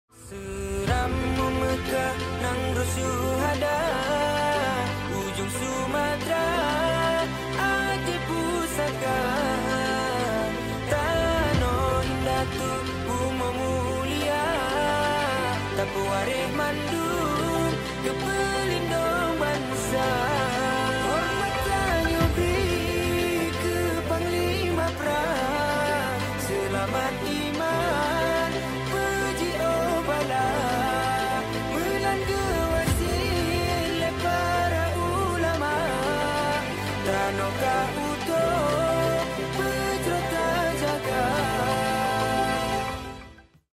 Angin Badai di venue panjat sound effects free download
Angin Badai di venue panjat tebing PON XXI ACEH SUMUT 2024